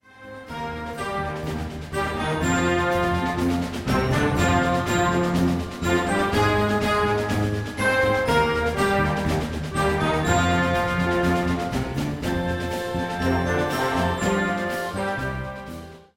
Gattung: Pop Beginning Band Serie
Besetzung: Blasorchester